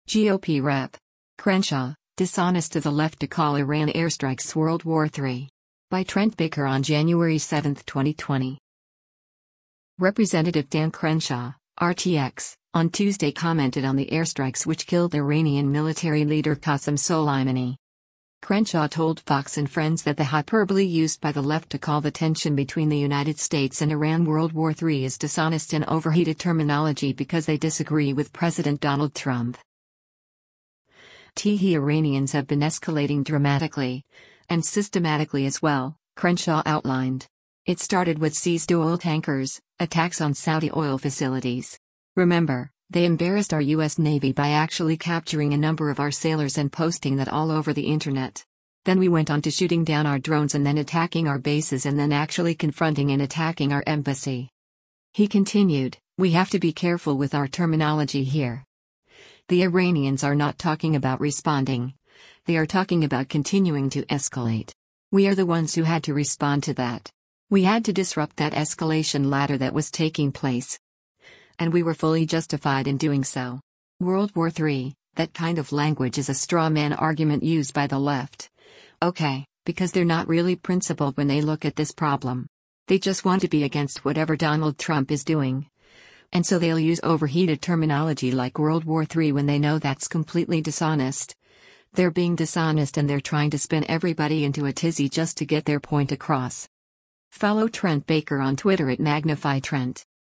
Crenshaw told “Fox & Friends” that the hyperbole used by the left to call the tension between the United States and Iran “World War III” is “dishonest” and “overheated” terminology because they disagree with President Donald Trump.